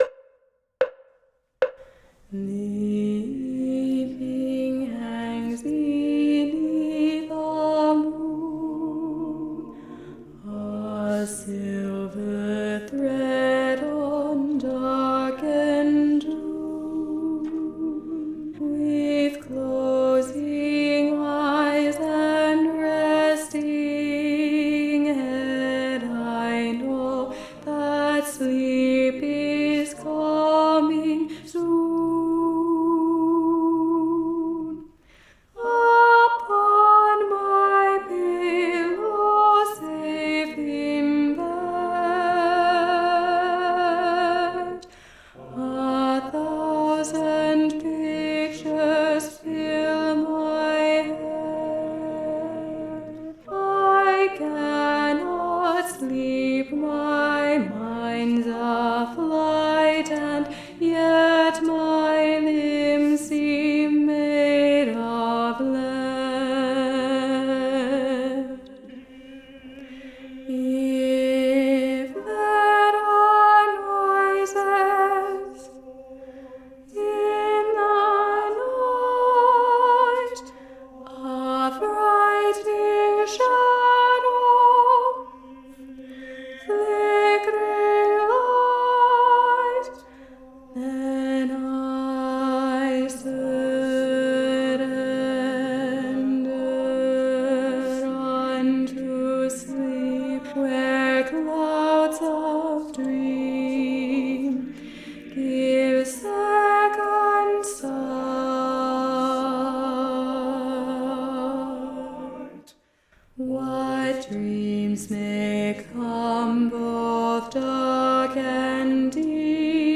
- Œuvre pour chœur à 8 voix mixtes (SSAATTBB)
Alto 1 Live Vocal Practice Track